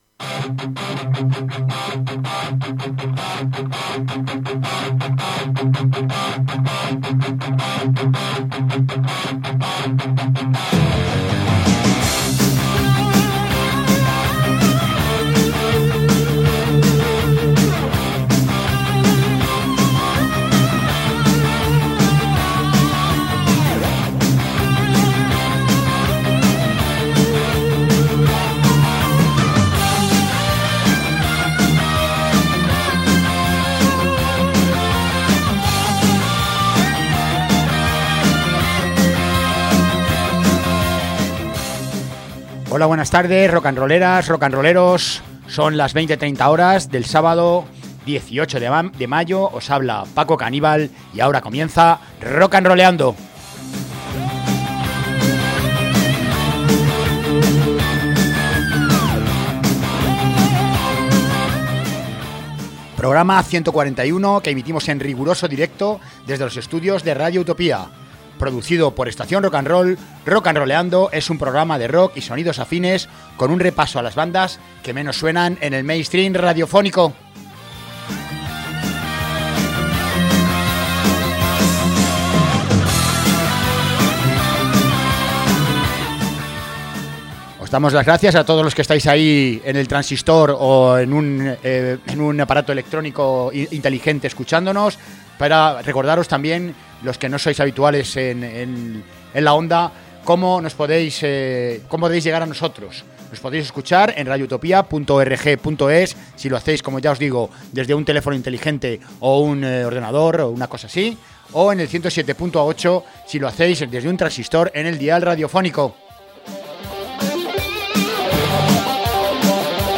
Su música actual es, básicamente, metal alternativo y metal con electrónica (metal industrial).